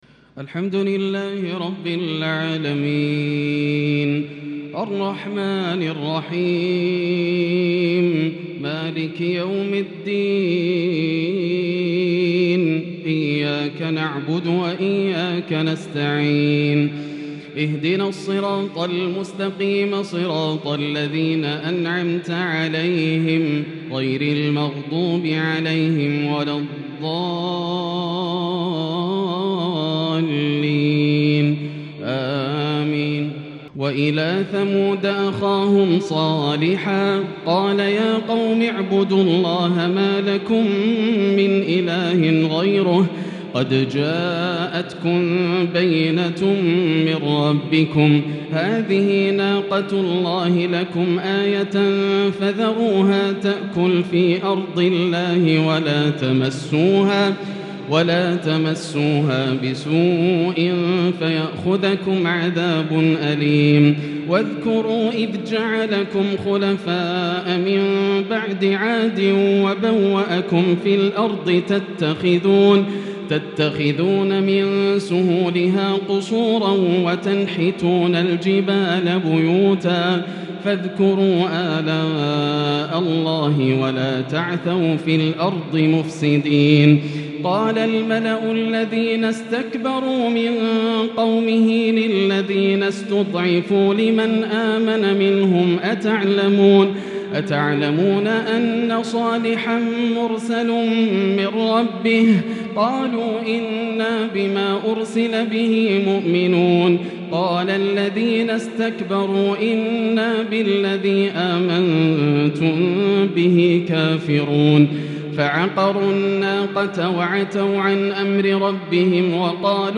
تراويح ليلة 11 رمضان 1442هـ من سورة الأعراف (73-141) |taraweeh 11st niqht ramadan Surah Al-A’raf 1442H > تراويح الحرم المكي عام 1442 🕋 > التراويح - تلاوات الحرمين